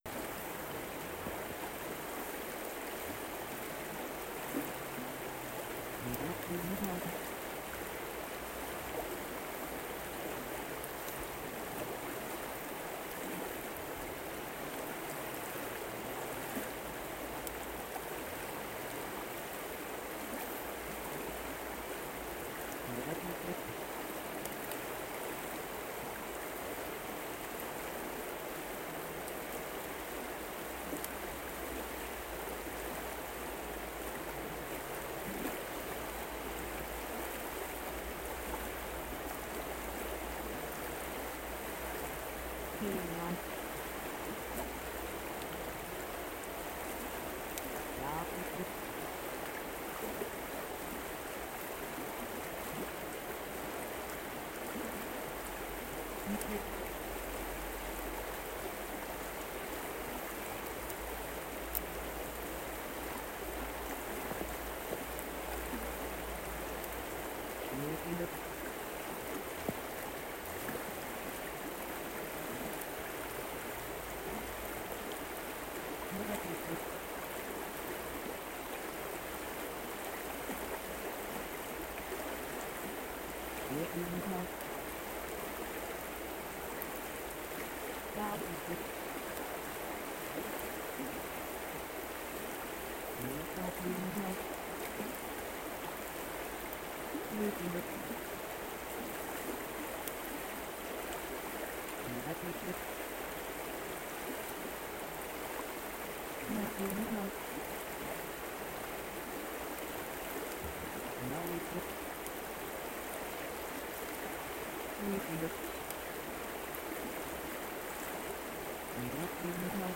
When you play it, the sound isn’t what the label promised. The river is steady and quiet, but something feels off with the sound.
river-flows.wav